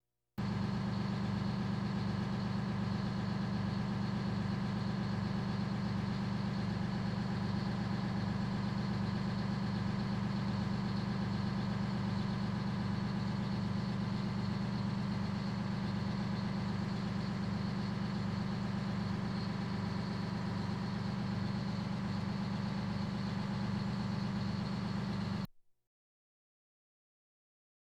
transport
Fire Truck Idling